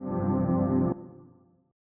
When you set the release to 0%, the note turns off immediately after you release it.
Example: Ambient Pads with release at 0%.